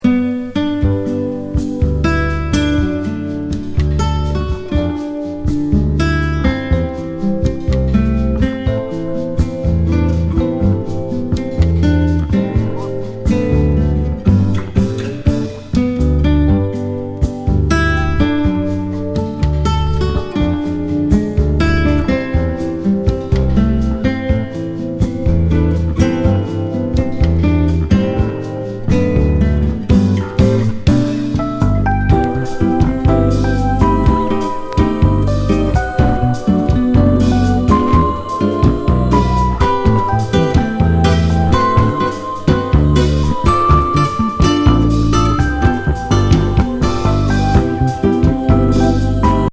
トロピカルAOR